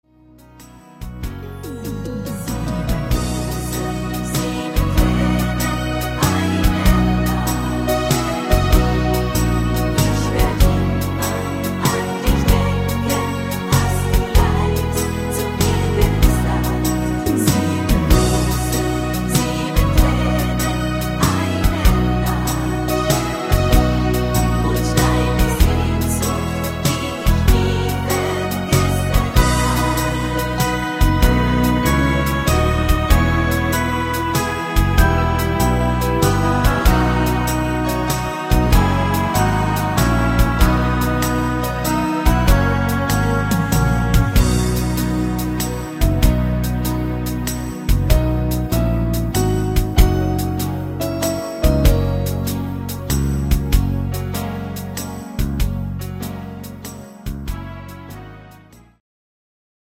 Easy to sing minus 3